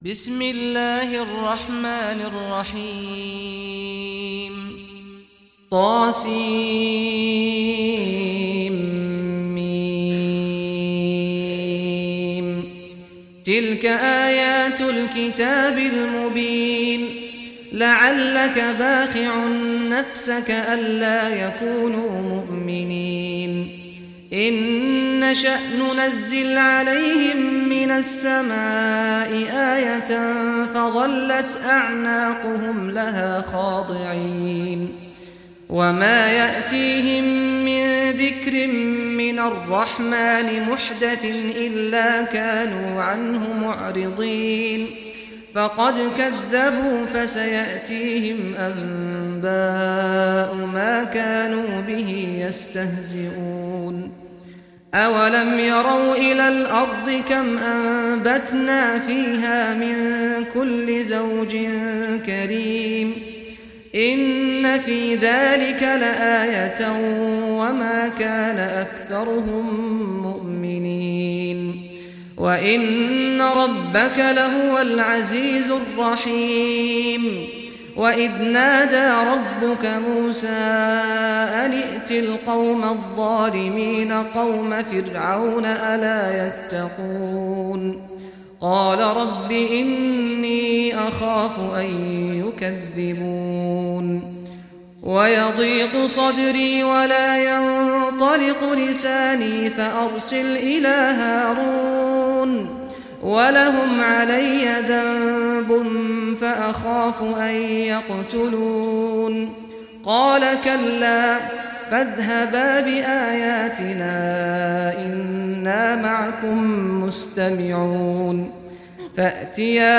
Surah Ash Shuara Recitation by Sheikh Saad Ghamdi
Surah Ash Shuara, listen or play online mp3 tilawat / recitation in Arabic in the beautiful voice of Sheikh Saad Al Ghamdi.